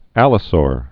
(ălə-sôr)